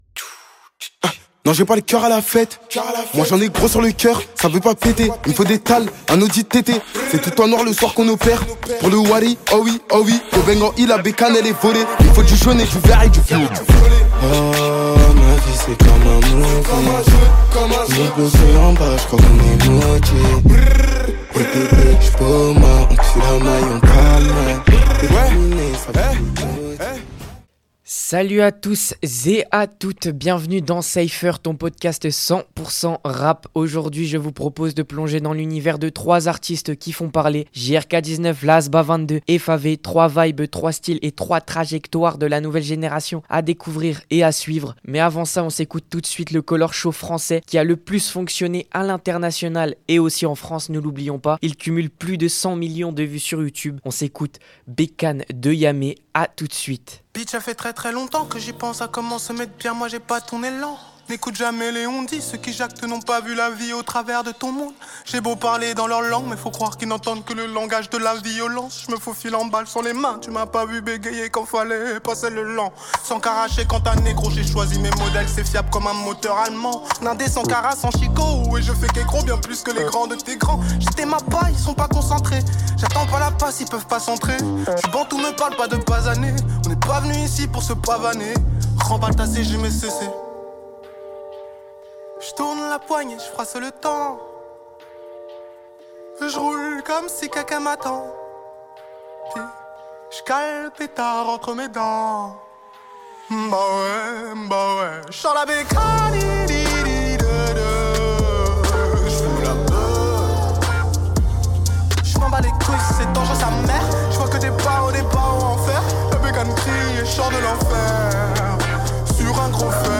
Cypher ton émission 100% rap ! N'2